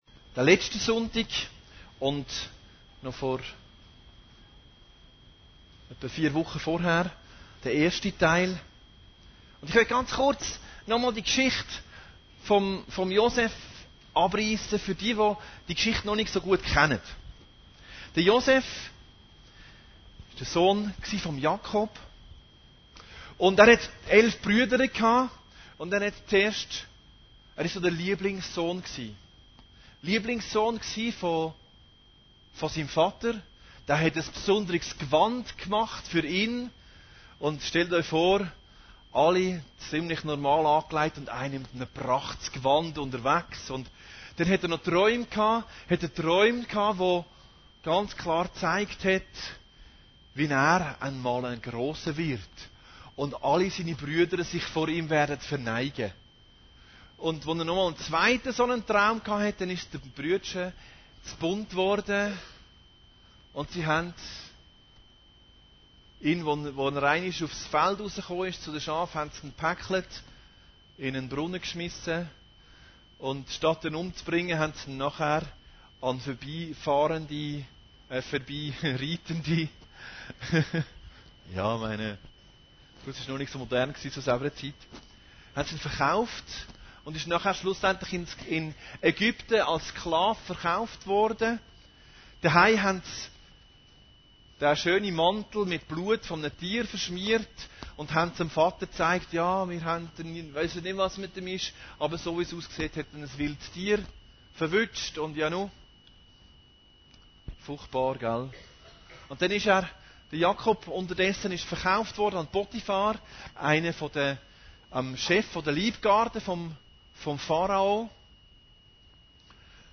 Predigten Heilsarmee Aargau Süd – Josef 3 Versöhnung